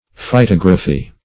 Search Result for " phytography" : The Collaborative International Dictionary of English v.0.48: Phytography \Phy*tog"ra*phy\, n. [Phyto- + -graphy: cf. F. phytographie.] The science of describing plants in a systematic manner; also, a description of plants.